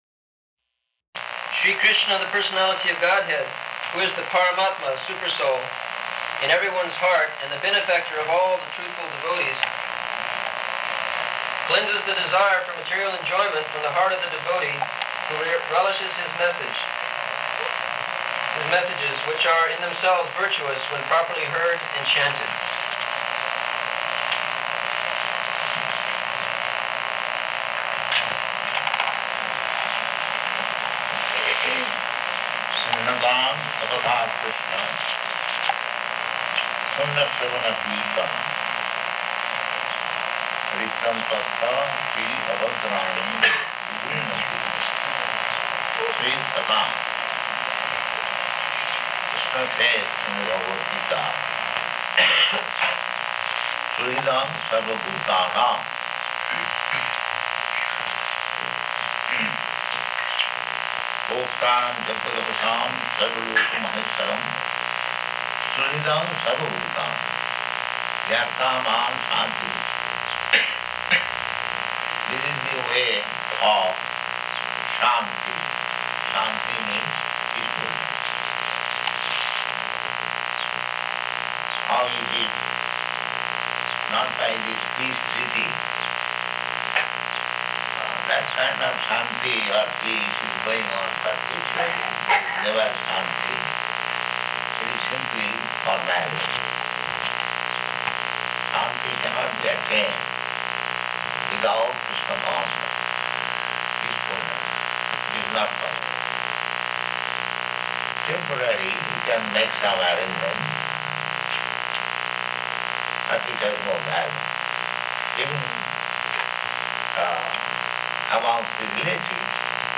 Location: New Vrindaban
[recorded on faulty equipment]